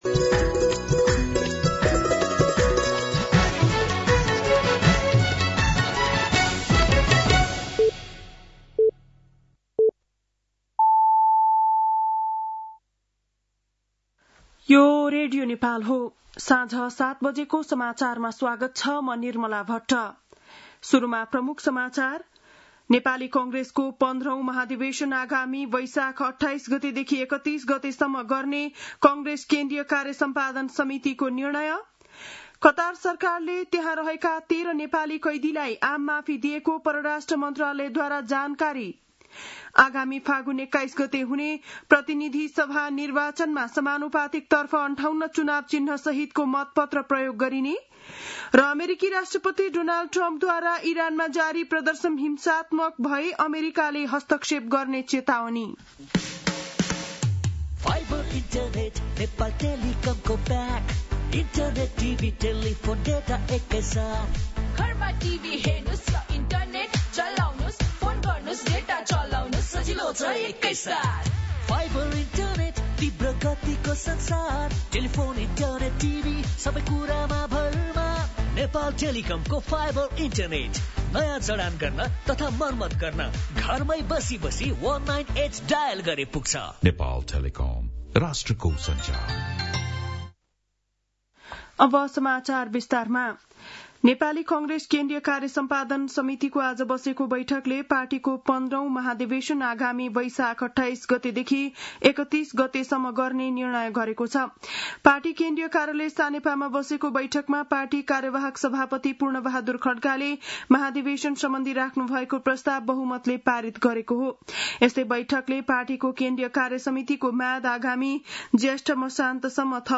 बेलुकी ७ बजेको नेपाली समाचार : १८ पुष , २०८२